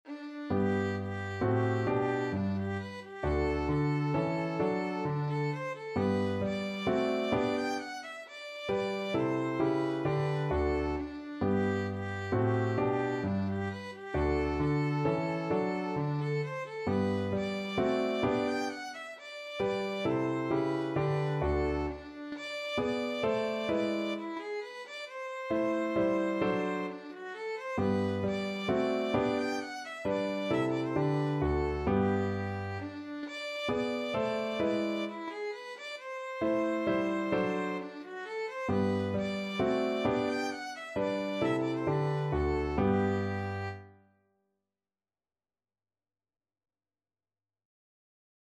3/4 (View more 3/4 Music)
= 132 Allegro (View more music marked Allegro)
Classical (View more Classical Violin Music)